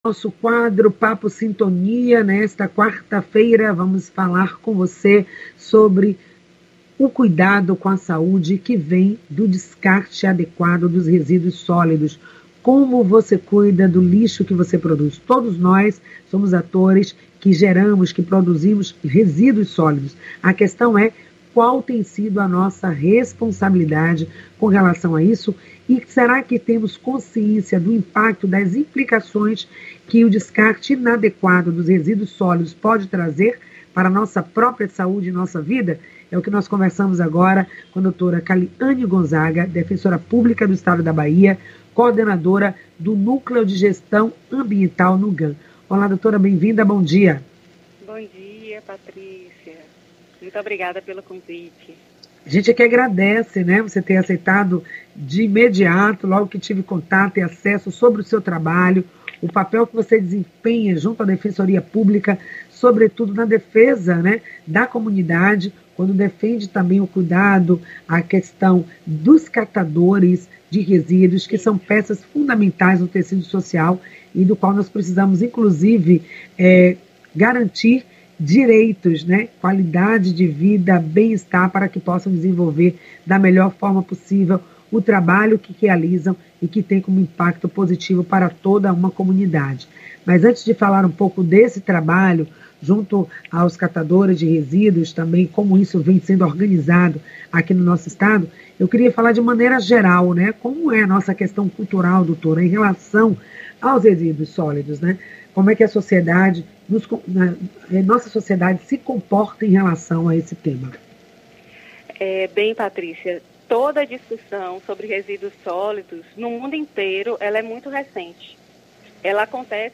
O programa Em Sintonia acontece de segunda a sexta das 9 às 10h, pela Rádio Excelsior AM 840.